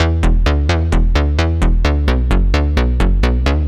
Index of /musicradar/80s-heat-samples/130bpm
AM_OB-Bass_130-E.wav